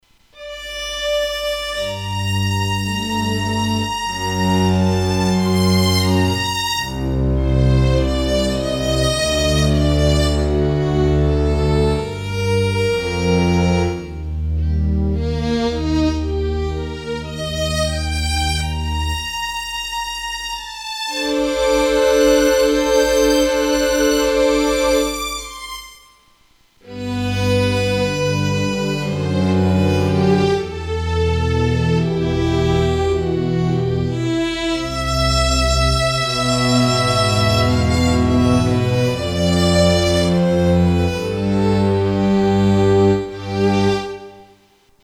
gv-violini 2013.mp3